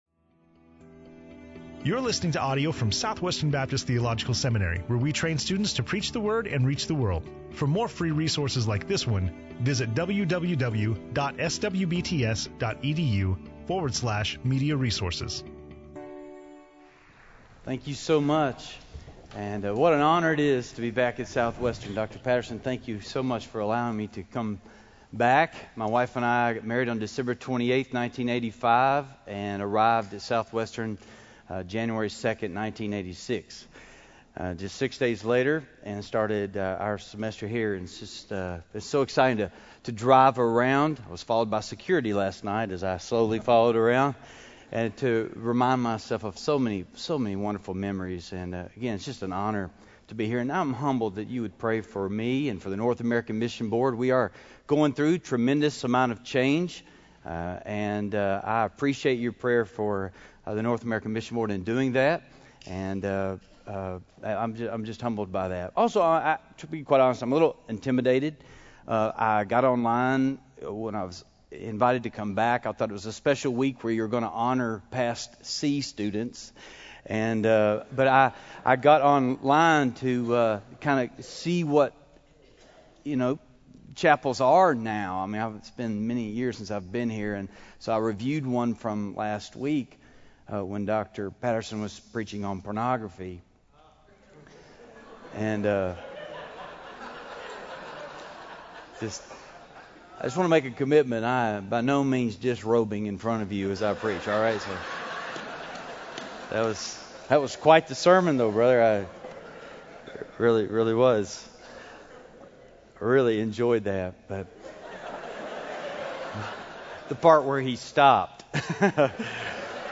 SWBTS Chapel